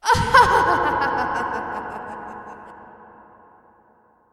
女孩邪恶的笑声 " 邪恶的笑声 1
描述：来自Wayside School的Sideways Stories的录制带来了邪恶的笑声。混响补充道。
Tag: 闲扯 笑声 女孩 女性 邪恶的 女人